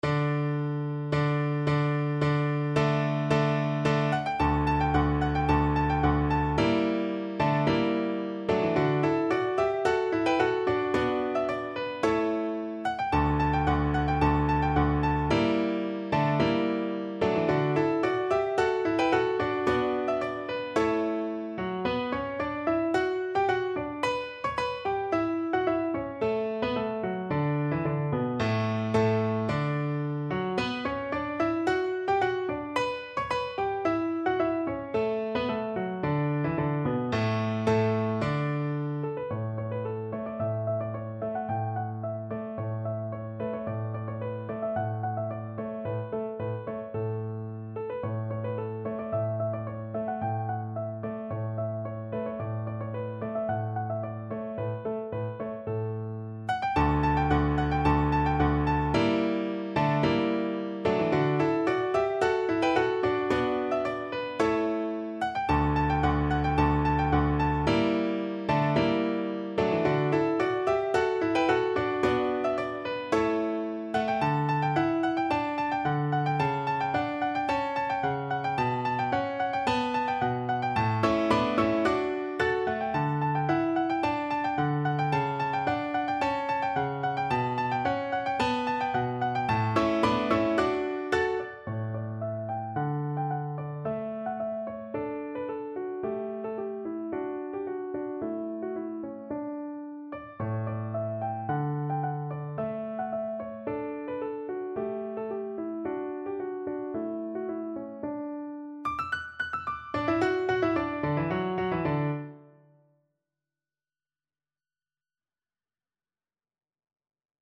D major (Sounding Pitch) (View more D major Music for Viola )
= 110 Allegro di molto (View more music marked Allegro)
Classical (View more Classical Viola Music)